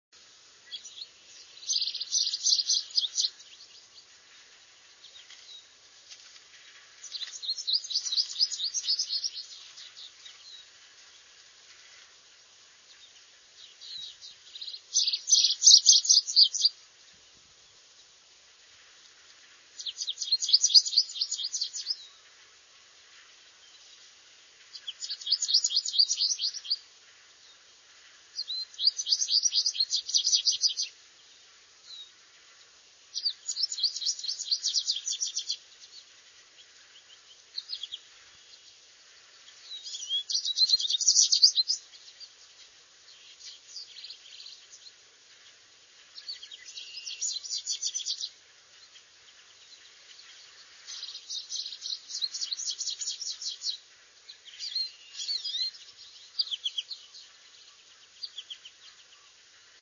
Goldfinch
Springtime song.  Large numbers of Goldfinch nest in the trees and shrubs of the river floodplain in spring.
goldfinchsong_flight_call_529.wav